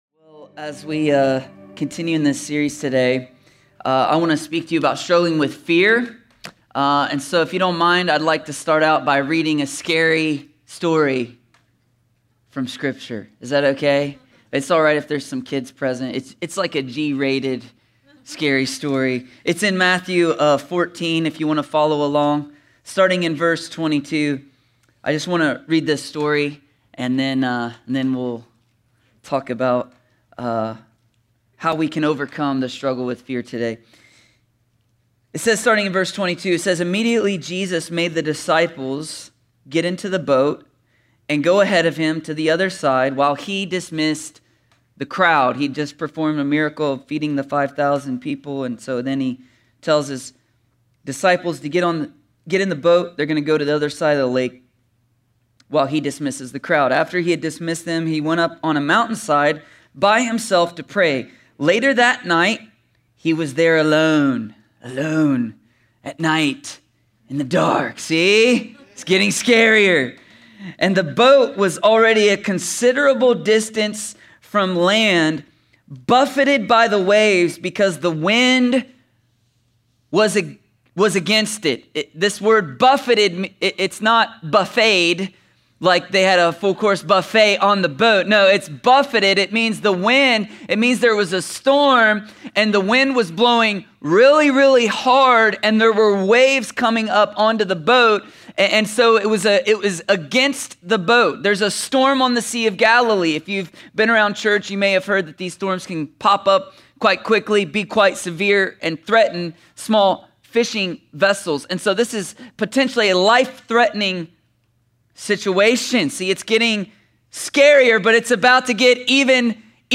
A sermon from the series “The Struggle Is Real.”…